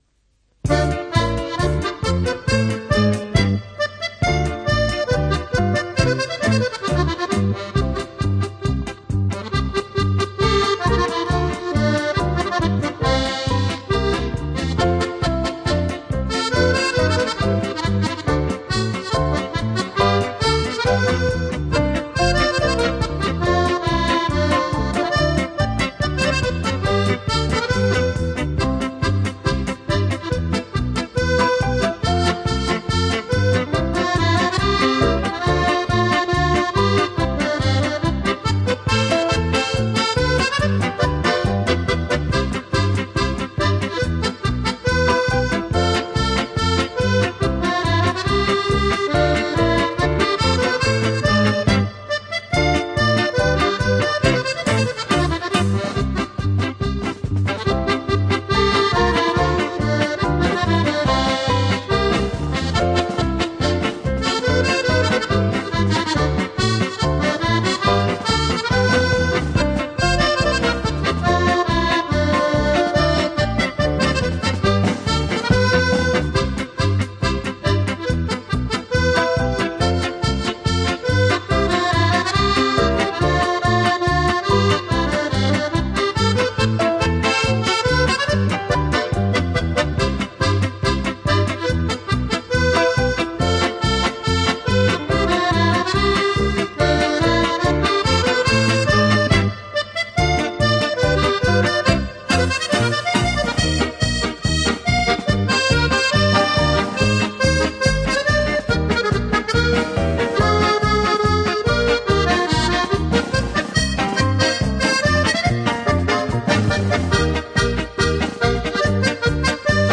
Жанр: Easy Listening, Accordeon